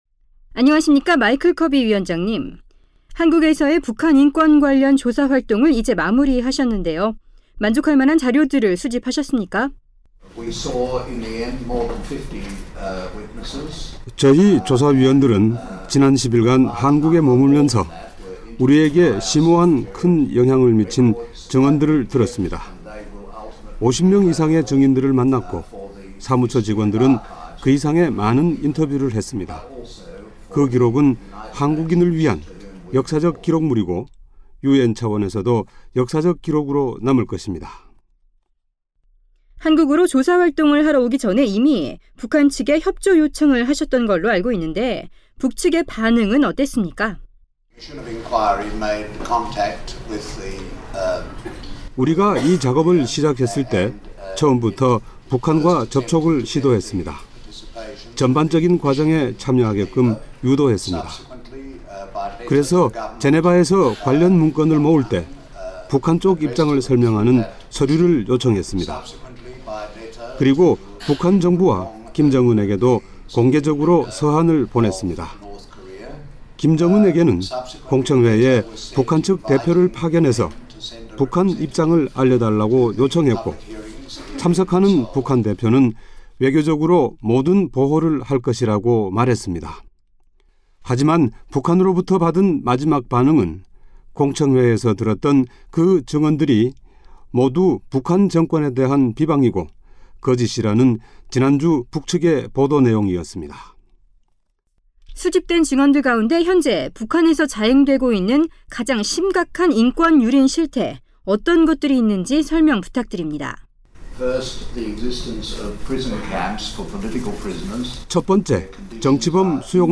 [인터뷰] 마이클 커비 유엔 북한인권조사위원장